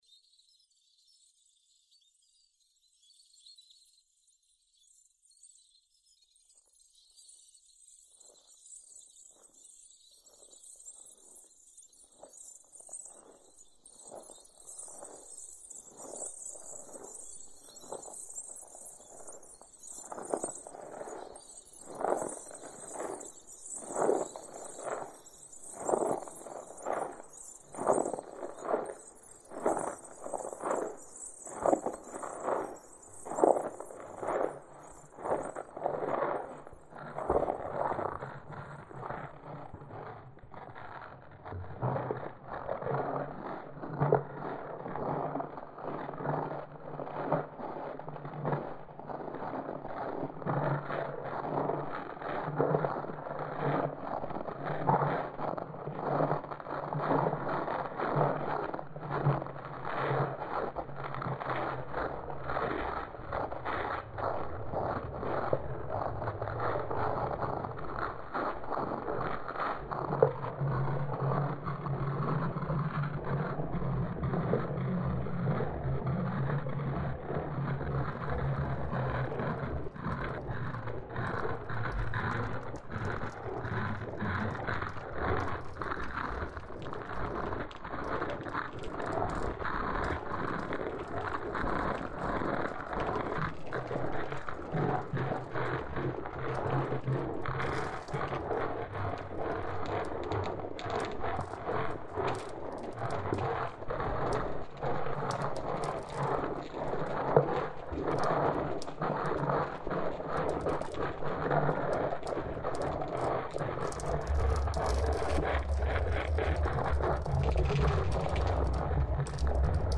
Processed Field Recordings
Refined collage-techniques